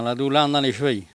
Langue Maraîchin
Patois
Catégorie Locution